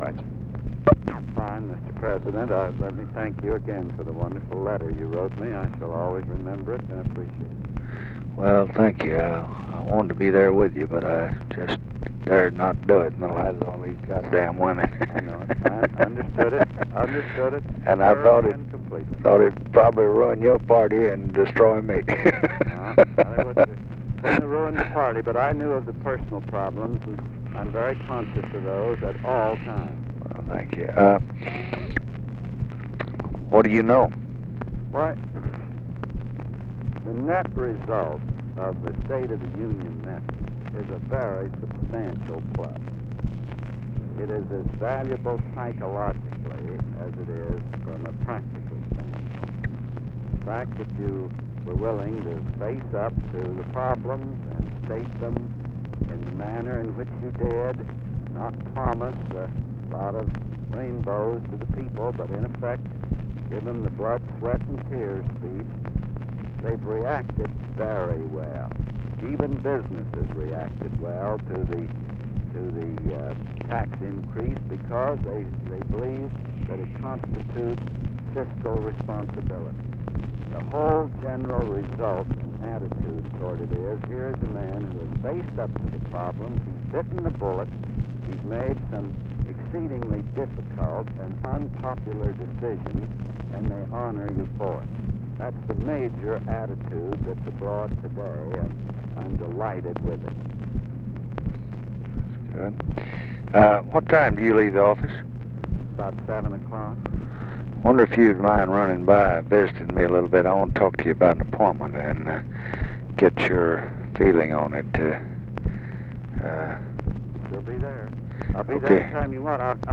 Conversation with CLARK CLIFFORD, January 16, 1967
Secret White House Tapes